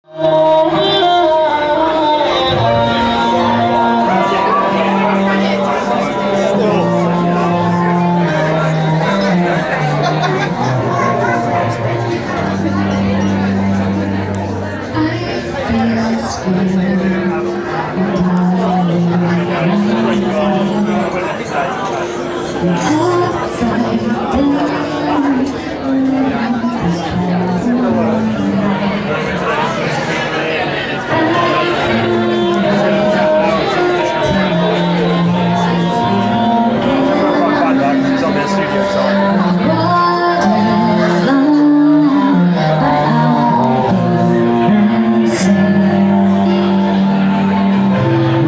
Crowd talking during The Idolins set.